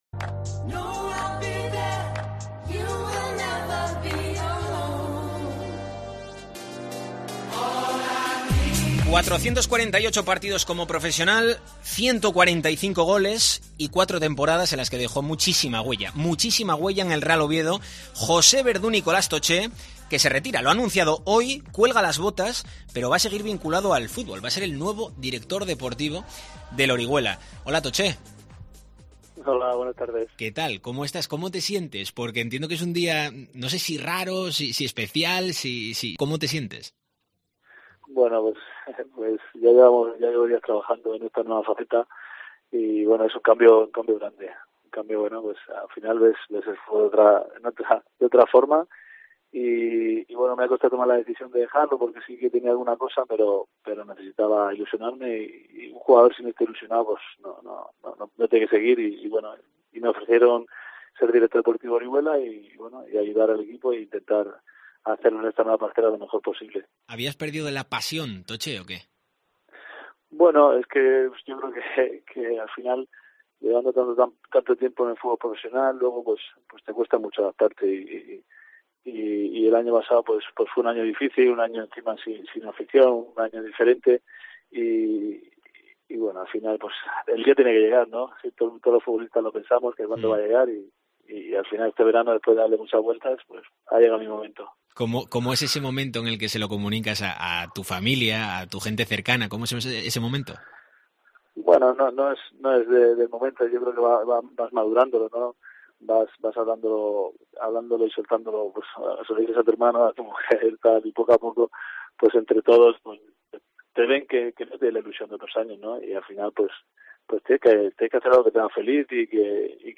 Entrevista a Toché en su retirada del fútbol